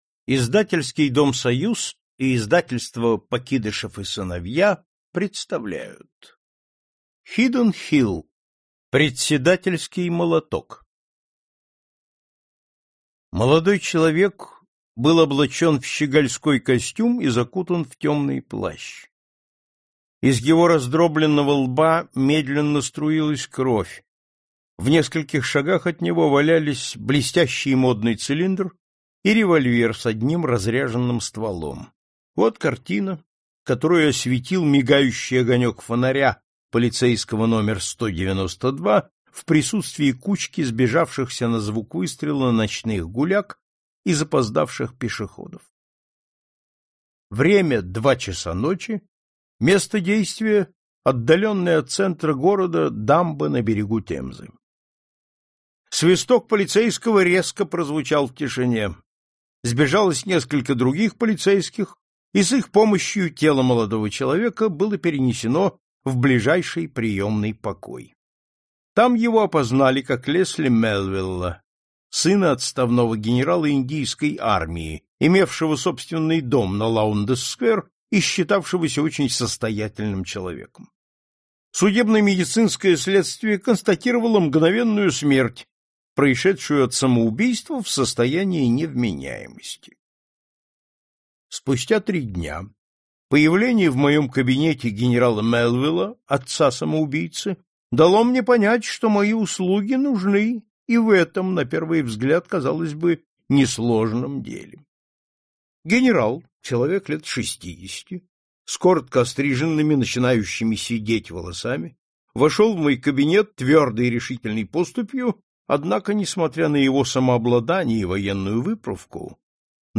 Аудиокнига Классика зарубежного детективного рассказа 4 | Библиотека аудиокниг